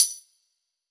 Bh Tamb.wav